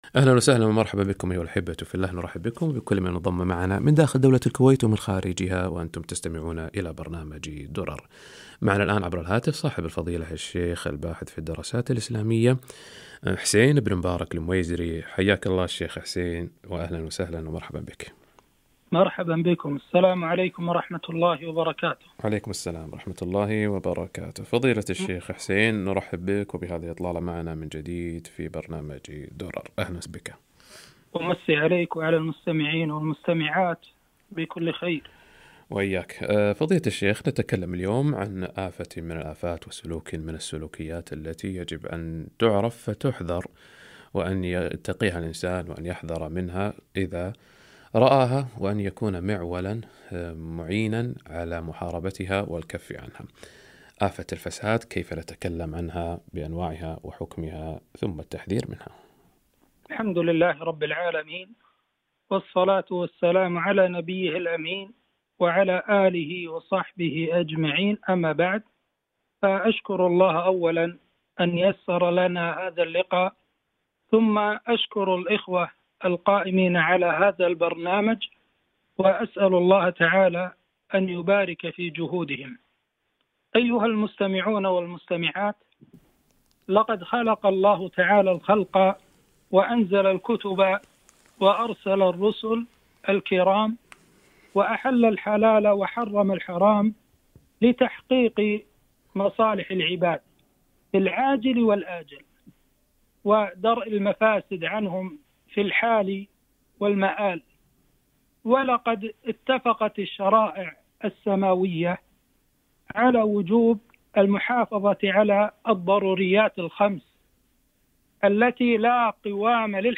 آفة الفساد وطرق مكافحتها - لقاء إذاعي